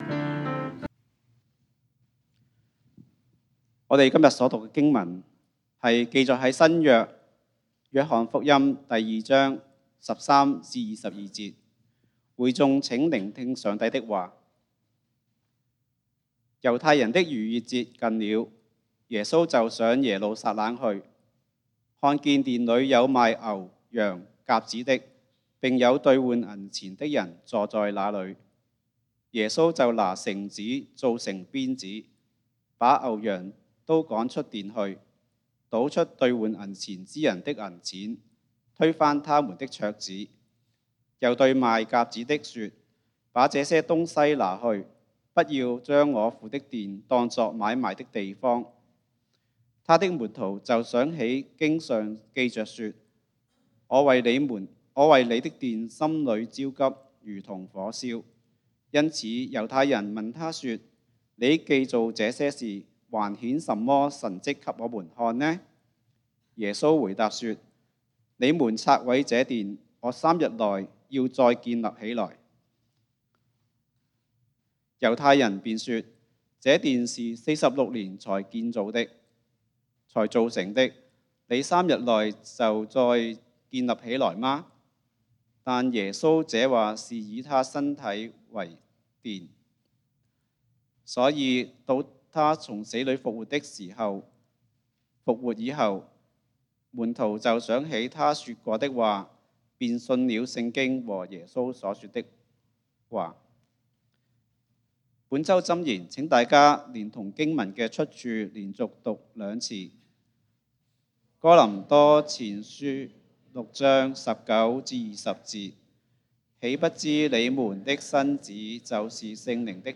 3/7/2021 講道經文：《約翰福音》 John 2-13-22 本週箴言：《哥林多前書》1 Corinthians 6:19-20 「豈不知你們的身子就是聖靈的殿麼？